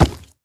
Minecraft Version Minecraft Version 1.21.5 Latest Release | Latest Snapshot 1.21.5 / assets / minecraft / sounds / mob / piglin_brute / step4.ogg Compare With Compare With Latest Release | Latest Snapshot
step4.ogg